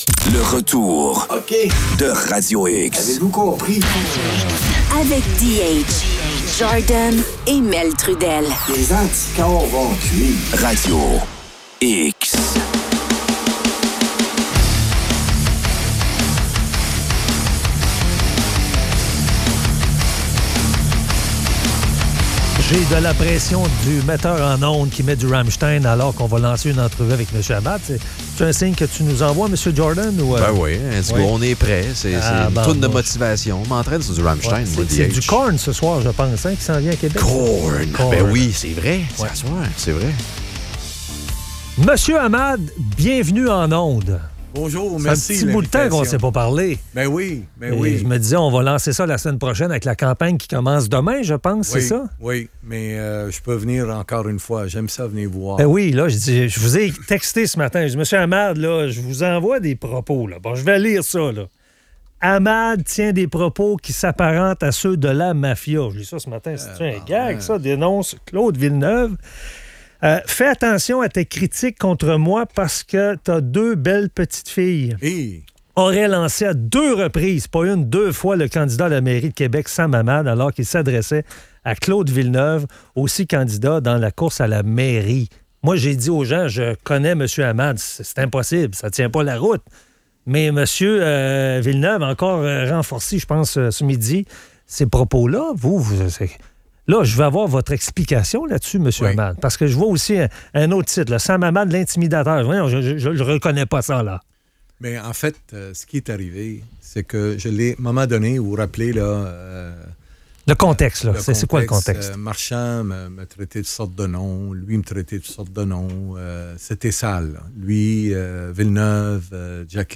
Entrevue avec Sam Hamad.